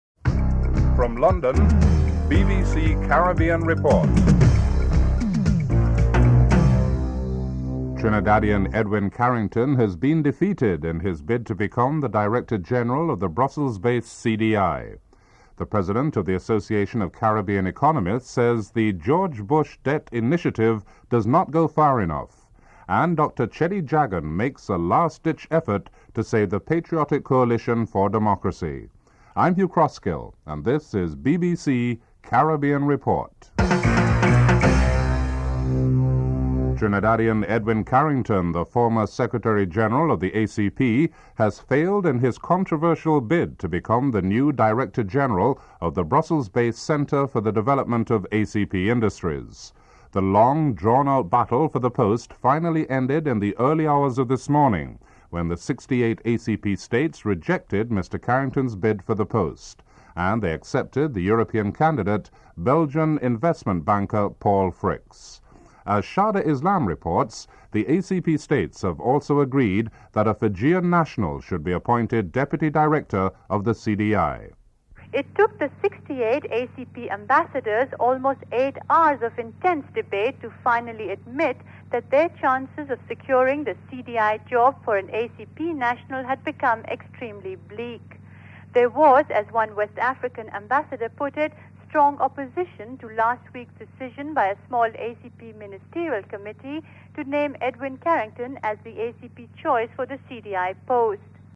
1. Headlines (00:00-00:35)
4. Financial News (06:54-07:27)